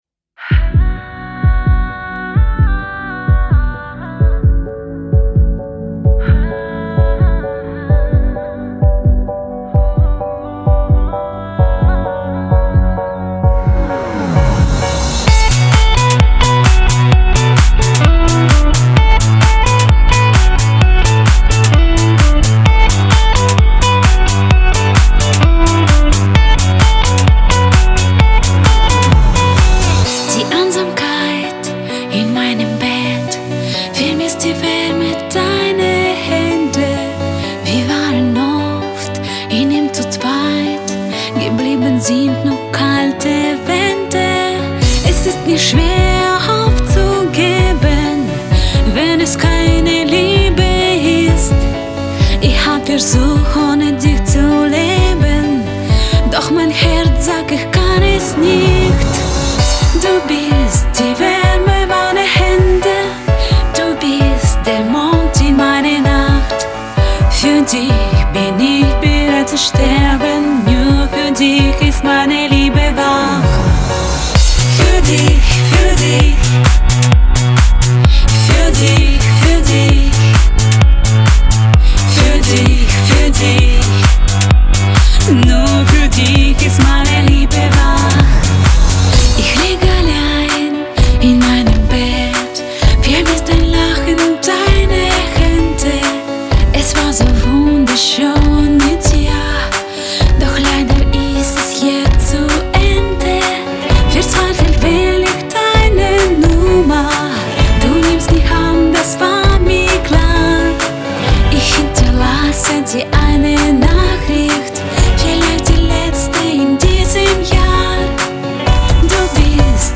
Ein schöner Popsong mit besonderem Akzent.